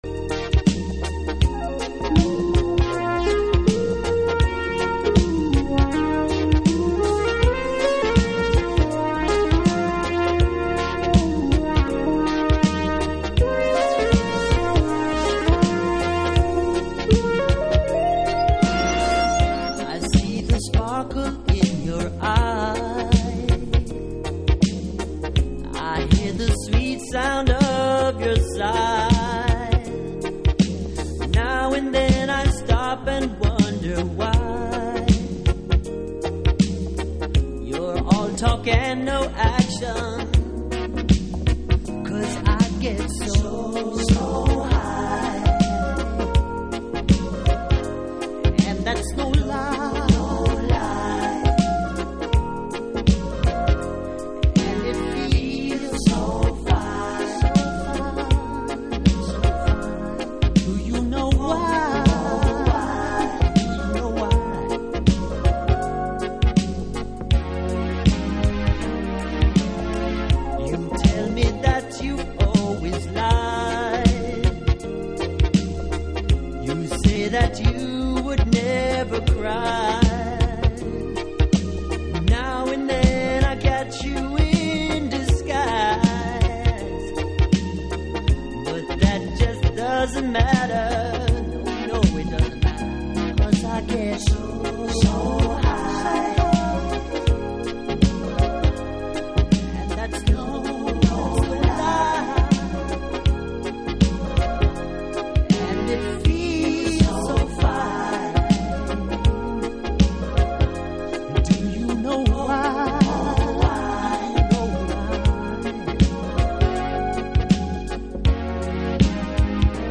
モダンソウルな趣ながら、愛らしいスペースシンセとミニマルなリズム隊が更にこのトラックを香しいものにしています。